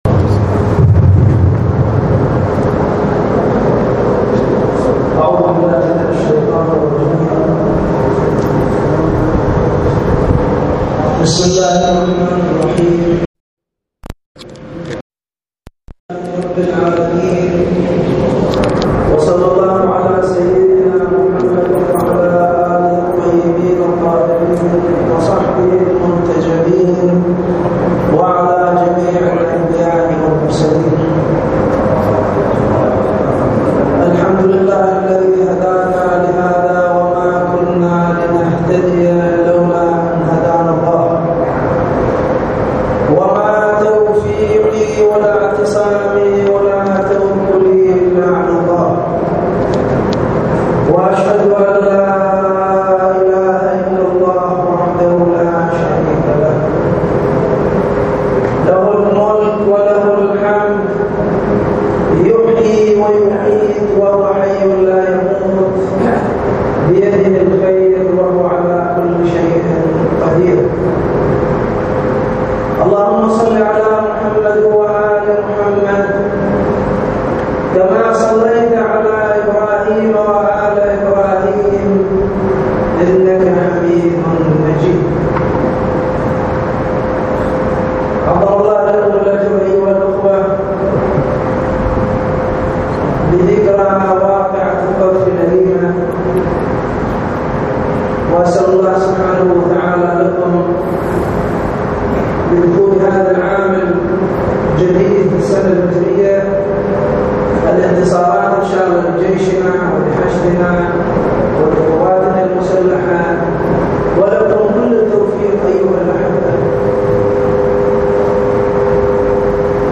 صلاة الجمعة في مدينة الناصرية - تقرير صوتي مصور -
للاستماع الى خطبة الجمعة الرجاء اضغط هنا